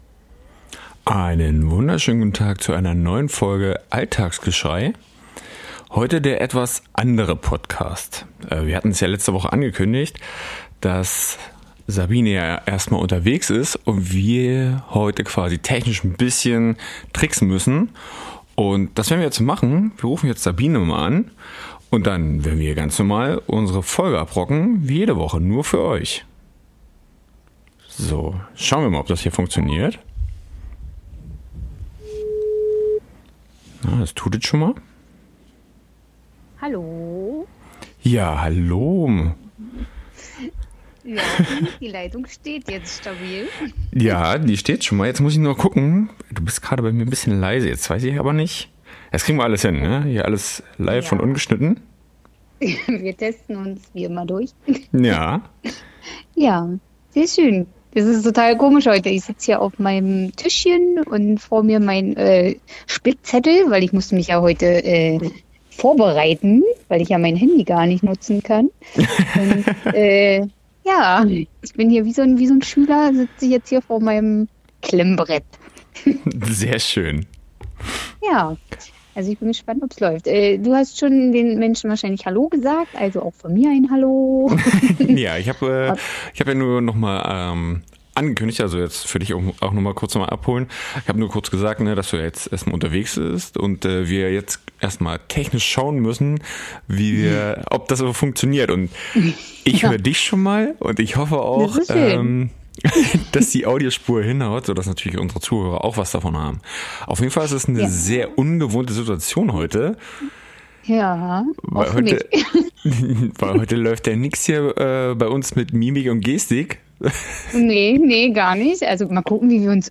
Beschreibung vor 2 Jahren Auch wenn wir dieses mal viele Kilometer von einander getrennt sind, machen wir für euch technisch alles möglich, dass ihr eure wöchentliche Dosis ALLTAGSGESCHREI bekommt. So erwarten euch dieses mal Themen wie, wann ist es am besten Geburtstag zu haben, ist Marvel besser als DC, wie handhaben wir das mit dem Rückgaberecht und wie immer zum Schluss, unsere Fun Facts.